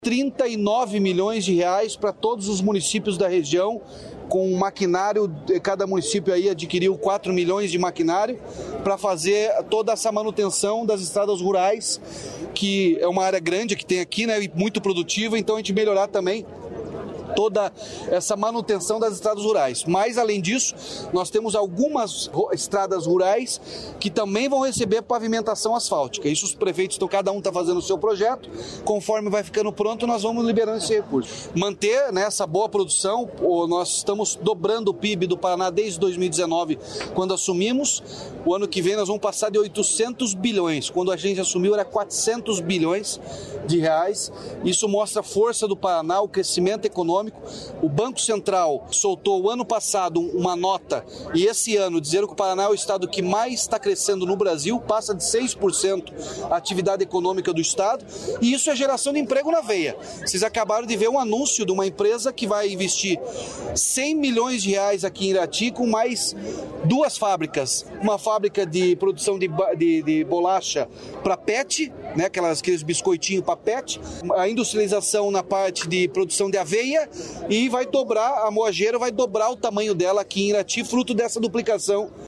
Sonora do governador Ratinho Junior sobre máquinas para estradas rurais da região Centro-Sul